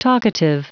Prononciation du mot talkative en anglais (fichier audio)
Prononciation du mot : talkative